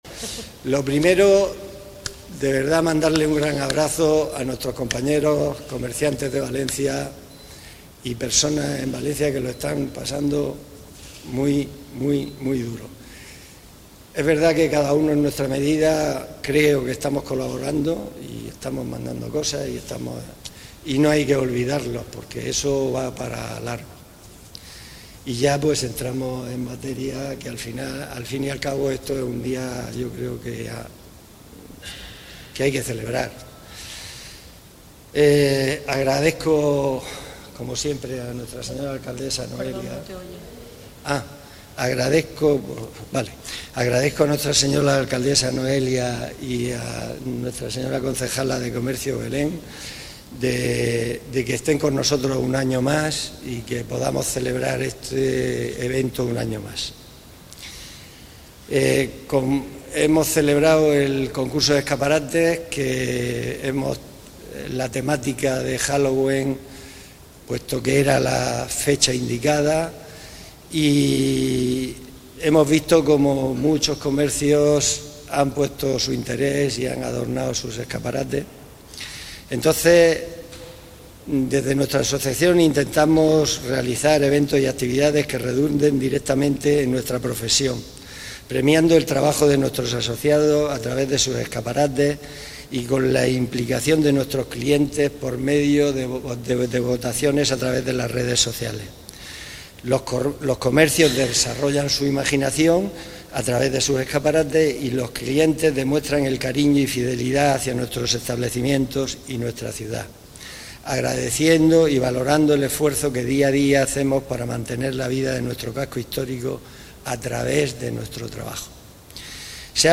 Declaraciones de la alcaldesa Noelia Arroyo